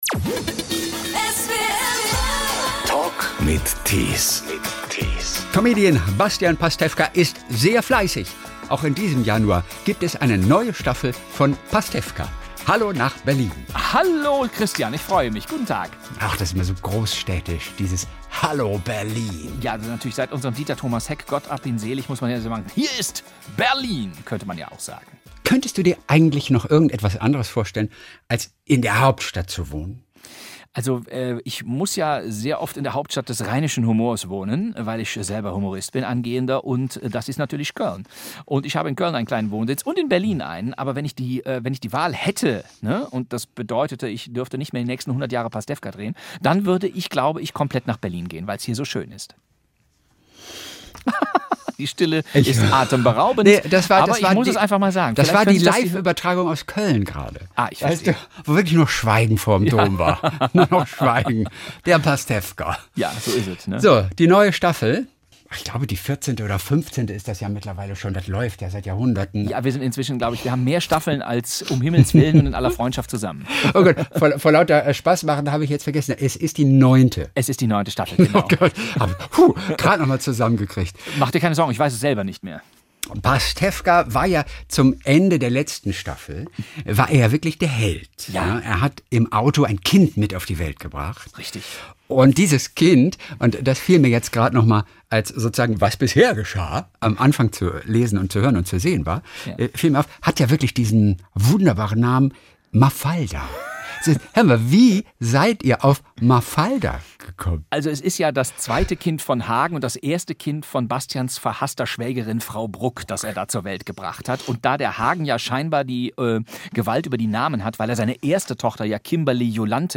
Der Talk in SWR 3